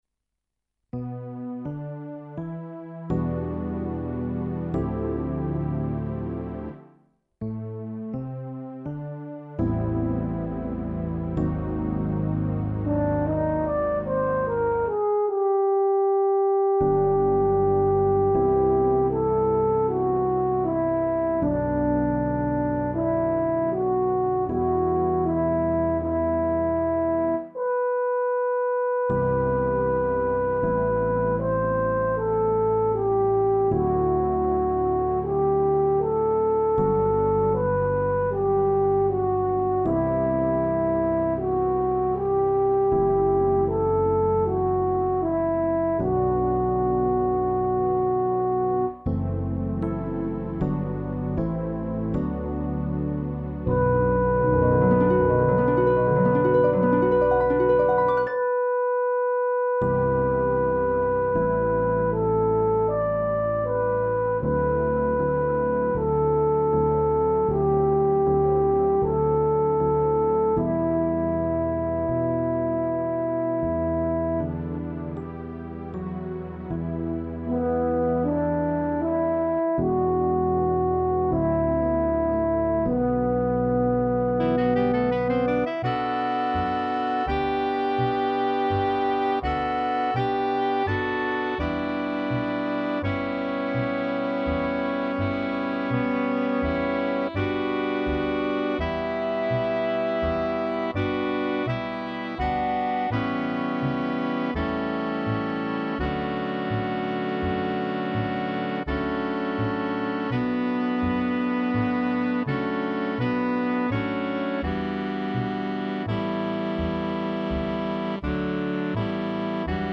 French Horn      Flugal / Trumpet
1  Trombone      5 Saxophones
Strings   Electric Bass   Harp   Drums